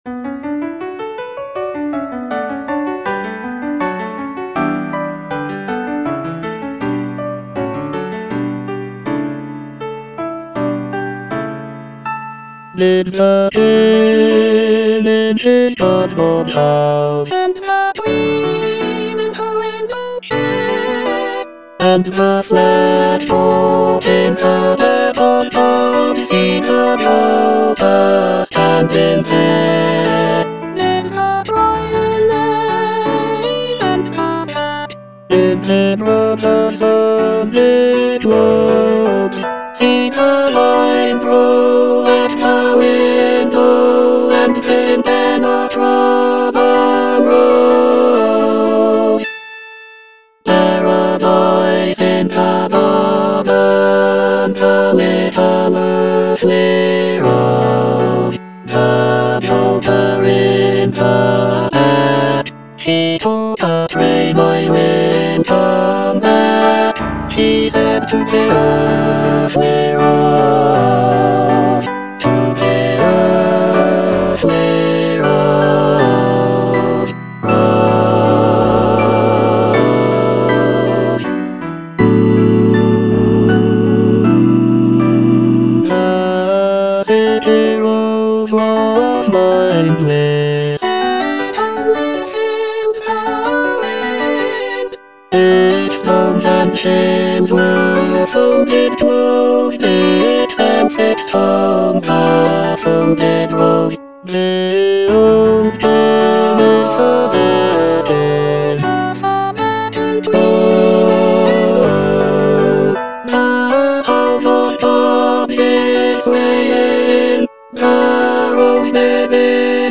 Bass (Emphasised voice and other voices)